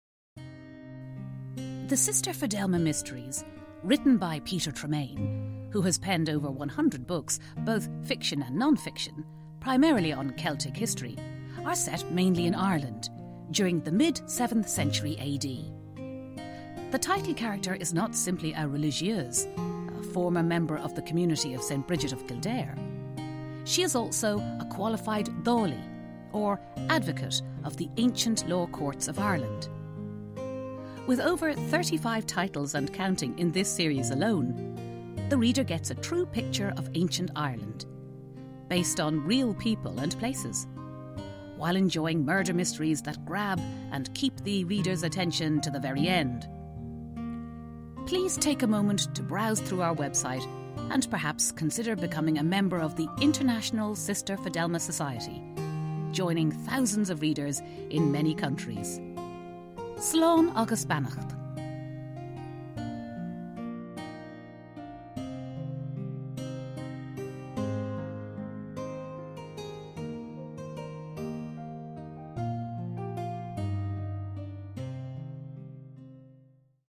Introduction by “The Voice of Fidelma